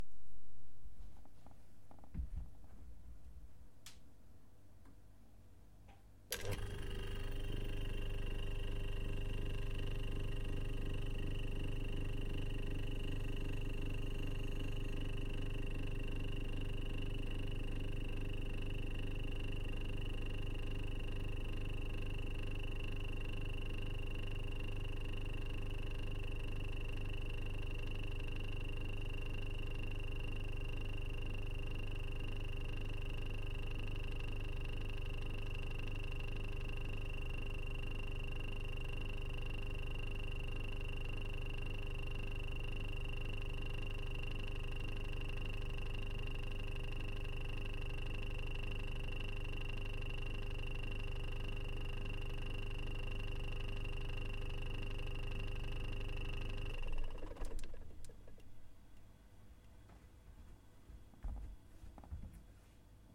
冰箱 " 最古老的冰箱(6)
描述：旧苏联冰箱。
Tag: 厨房 冰箱 电机 房子记录 冰箱 国内 发动机